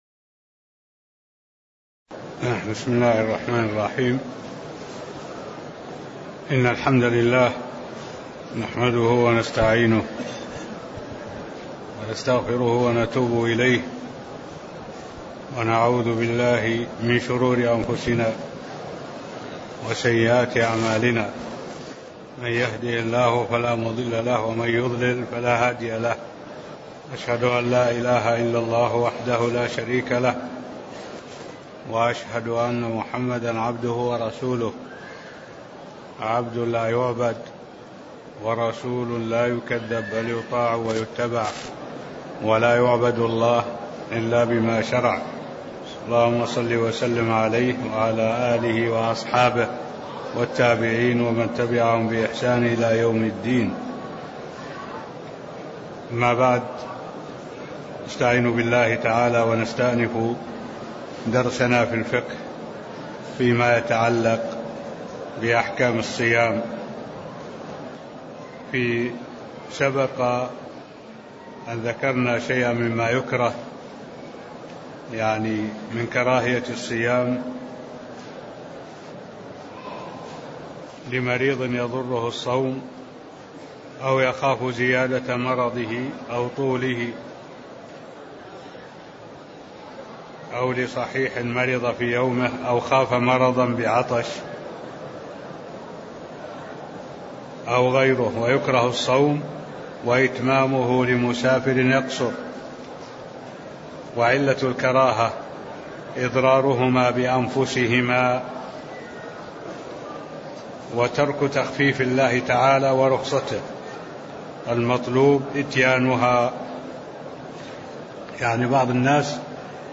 المكان: المسجد النبوي الشيخ: معالي الشيخ الدكتور صالح بن عبد الله العبود معالي الشيخ الدكتور صالح بن عبد الله العبود كتاب الصيام من قوله: (ويجوز وطأ لمن به مرض ينتفع به فيه) (18)